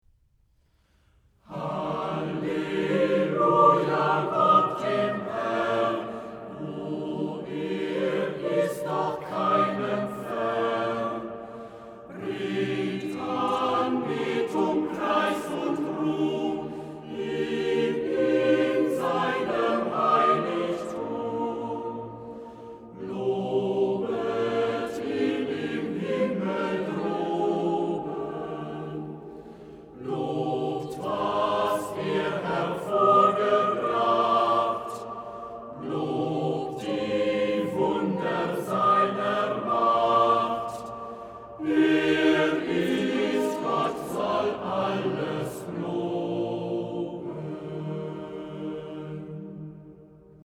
Chormusik zum Genfer Psalter, Berliner Domkantorei